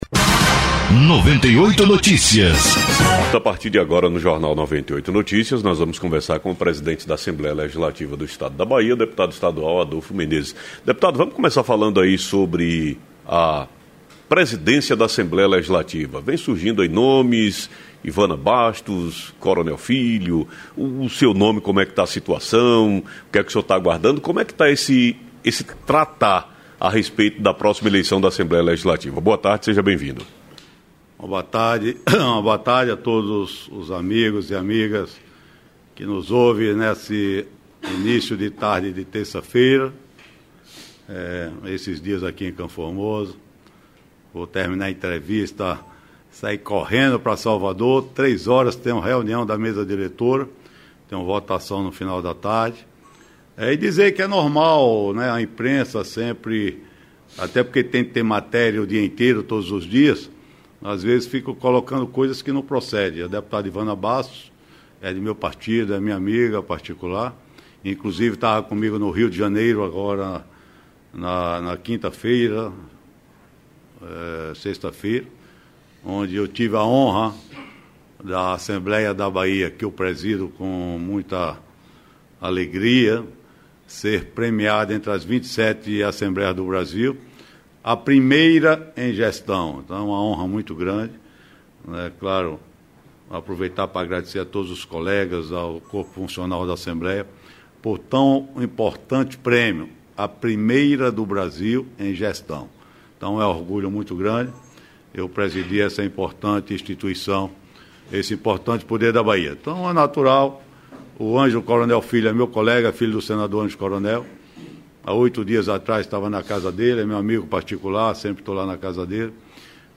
Entrevista ao vivo com o presidente da ALBA, deputado Adolfo Menezes